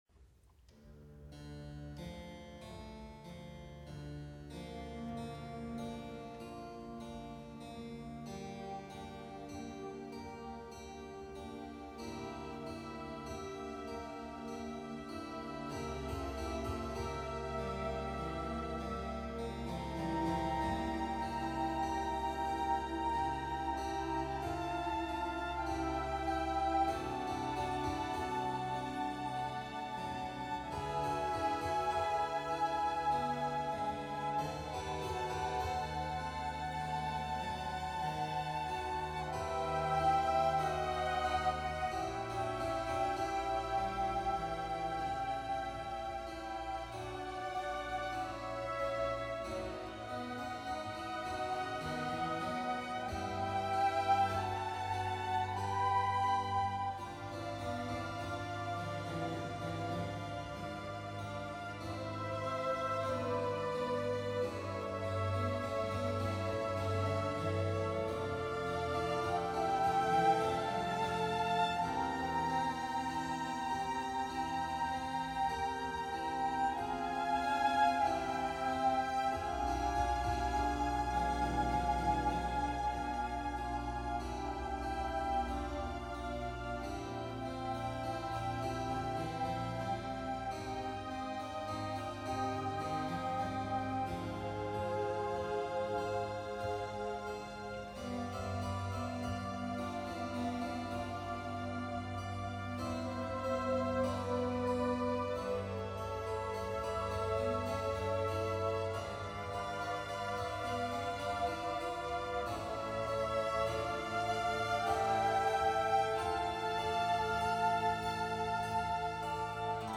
II. Adagio
第二乐章：沉睡的醉汉，缓板
缓慢的第二乐章越来越轻，表示睡得越来越熟。